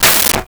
Toilet Seat Down 2
toilet-seat-down-2.wav